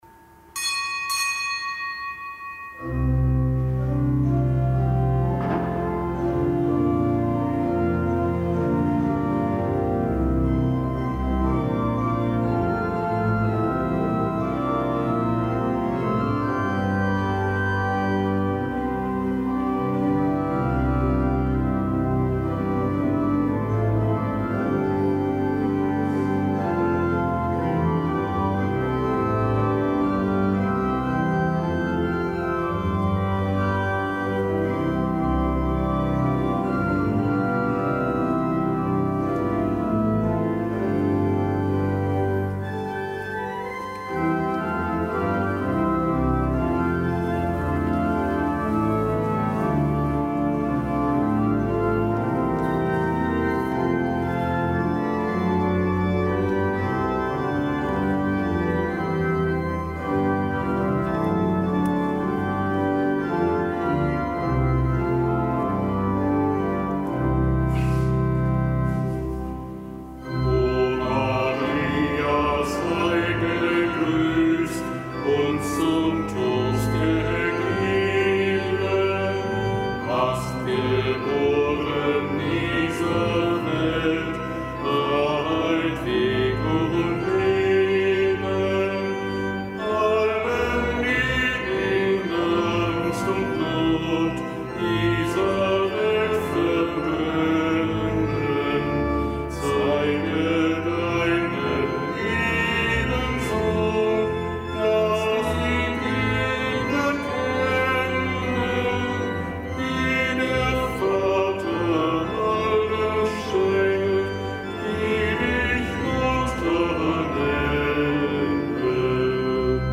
Kapitelsmesse aus dem Kölner Dom am Samstag der fünften Osterwoche.